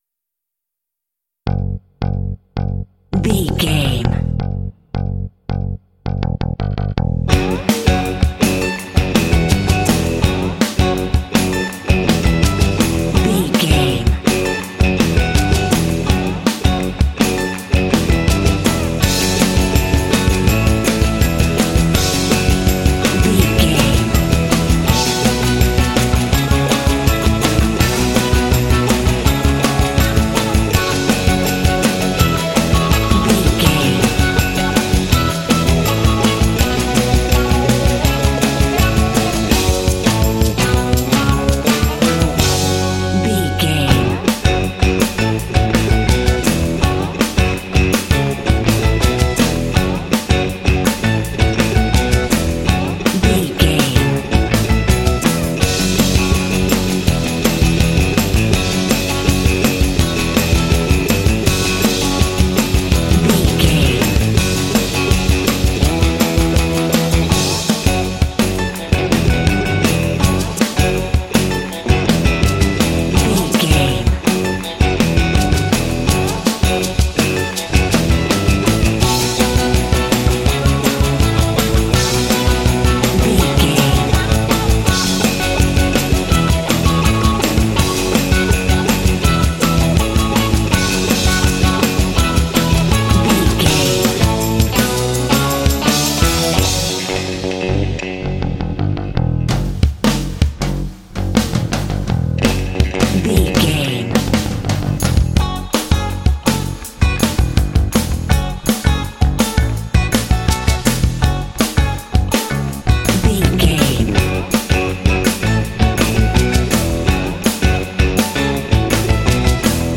Cool, groovy funk track ideal for action games.
Dorian
F#
funky
smooth
driving
bass guitar
electric guitar
drums
Funk
soul
motown